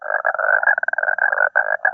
Rana
• (Rana temporaria)
rana.wav